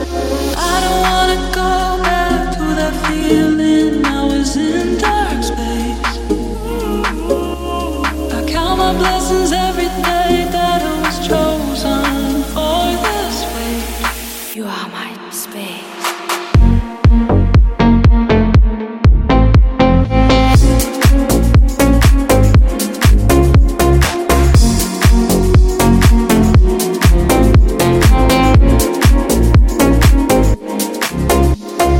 в современном кавере/ремиксе
House
Deep house Мощные басы Космические
Клубные